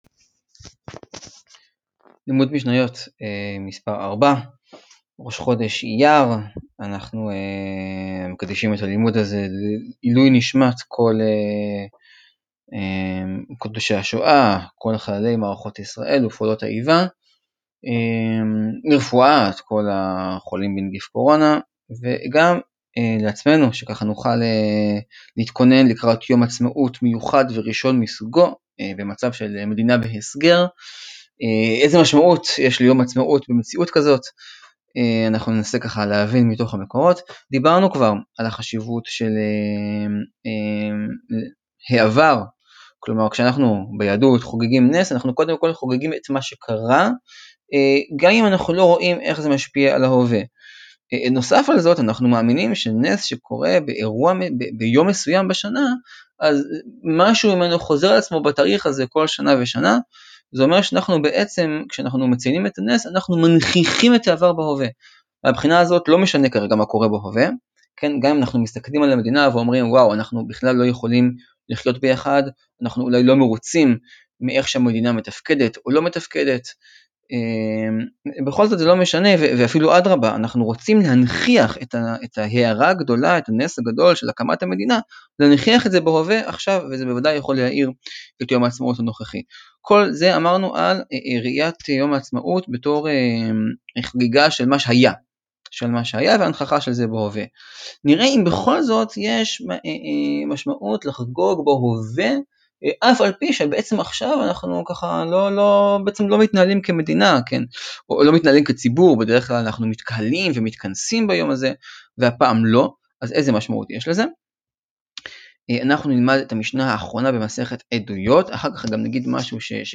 לימוד יומי בשאלה הנשאלת לראשונה מזה 72 שנה: האם יש משמעות לחגיגת עצמאות לבד בבית?